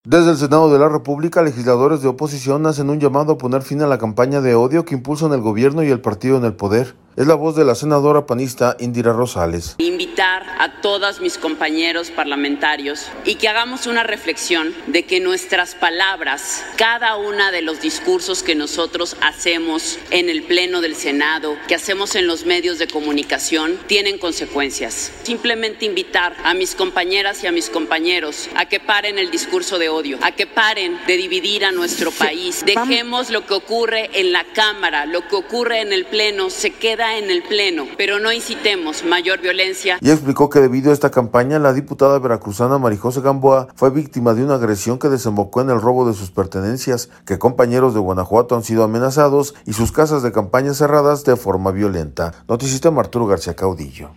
Desde el Senado de la República, legisladores de oposición hacen un llamado a poner fin a la campaña de odio que impulsan el gobierno y el partido en el poder. Es la voz de la senadora panista Indira Rosales.